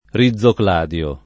vai all'elenco alfabetico delle voci ingrandisci il carattere 100% rimpicciolisci il carattere stampa invia tramite posta elettronica codividi su Facebook rizocladio [ ri zz okl # d L o ] s. m. (bot.); pl. ‑di (raro, alla lat., -dii )